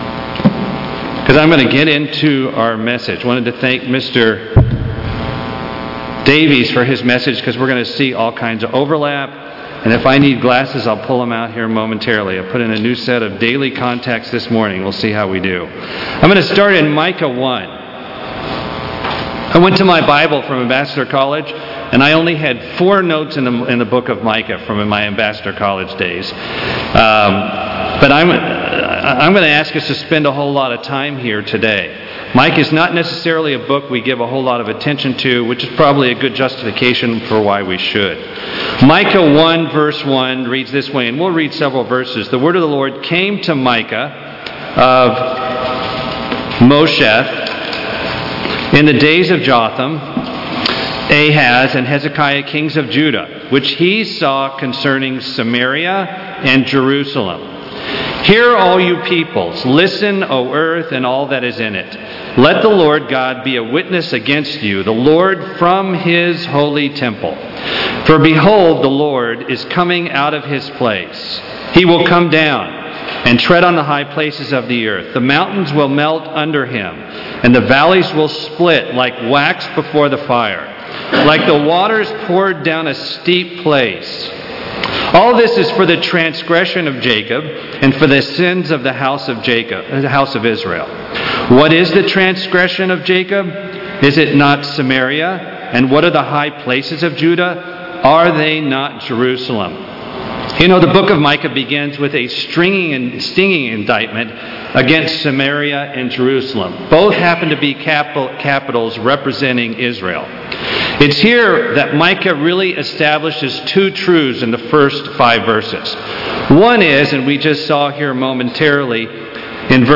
In the Book of Micah, God warns about the sins that the kingdom of Judah and Israel were commiting that would lead to their destruction. In this sermon